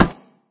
metalgrass.ogg